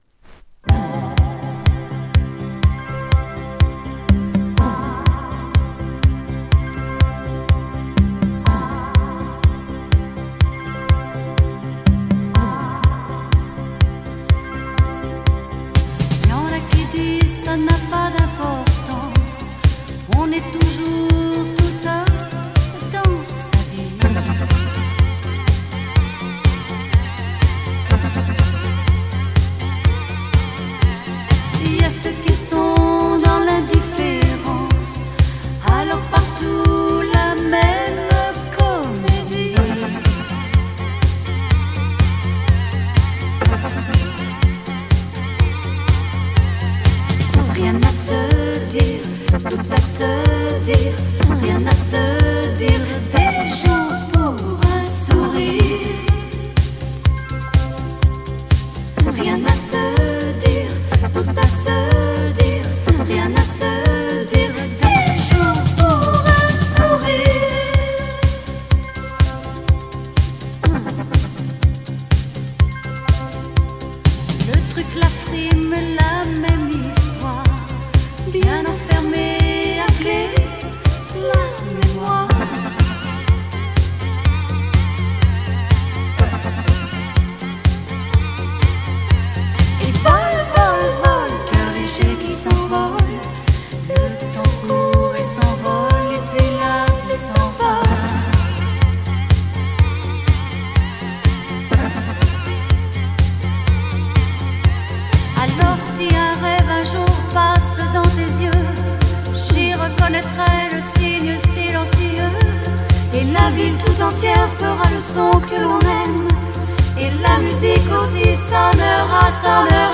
French Female Electro Pop-sike 80s